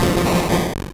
Cri de M. Mime dans Pokémon Rouge et Bleu.